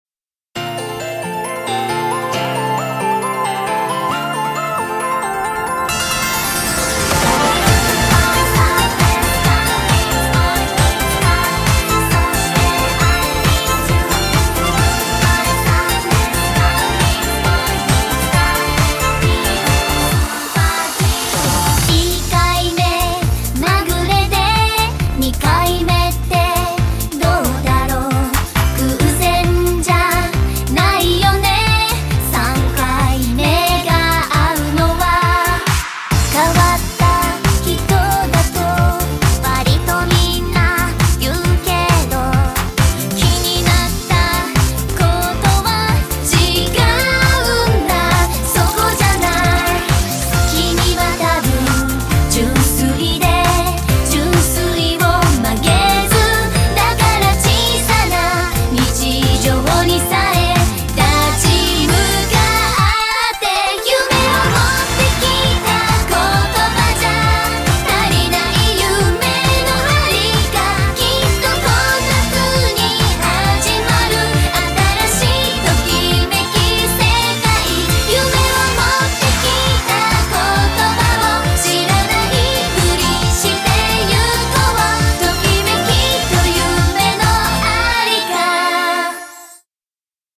BPM135-135
Audio QualityPerfect (High Quality)
It's not fun to step song which could be used as lullaby...